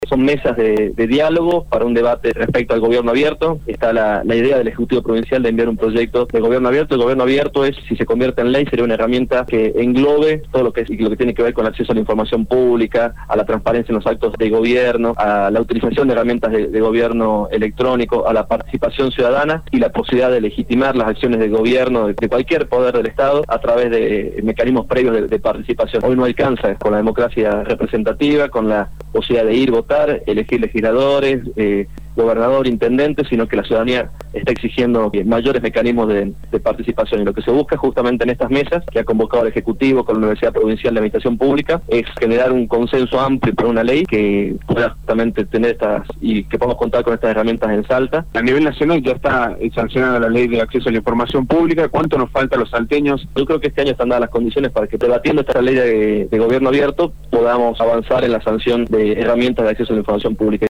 Matías Posadas, Diputado Provincial